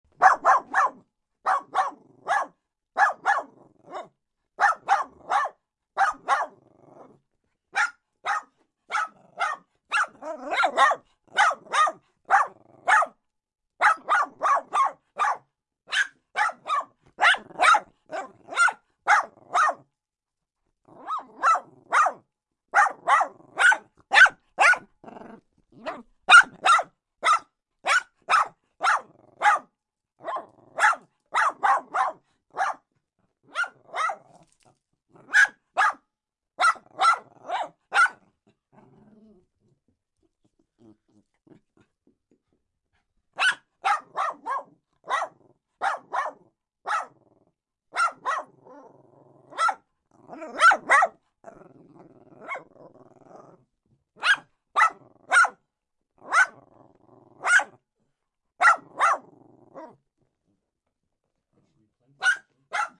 021020_small Dog Barking.wav Sound Effect Download: Instant Soundboard Button